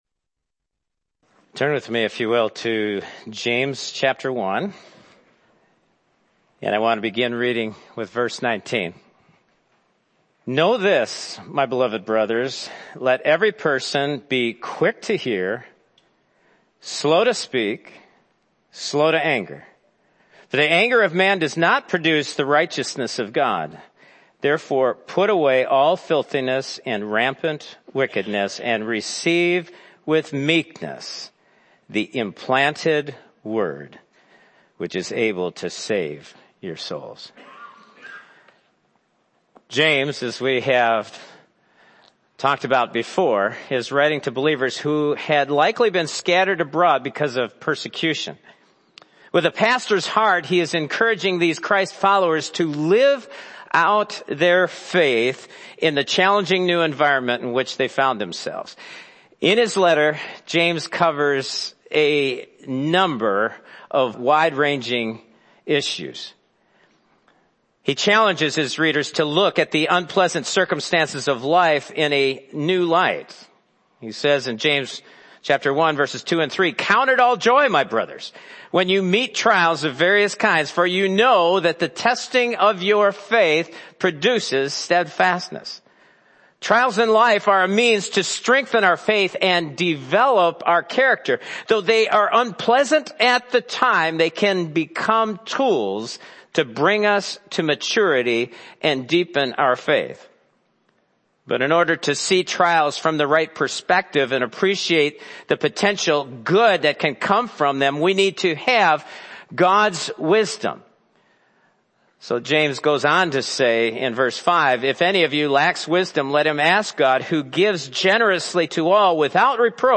James: Receiving the Implanted Word (1:19-21) – Harvest Community Church of Luxemburg, WI